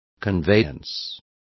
Complete with pronunciation of the translation of conveyance.